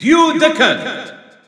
Announcer pronouncing Duck Hunt in French PAL.
Duck_Hunt_French_EU_Announcer_SSBU.wav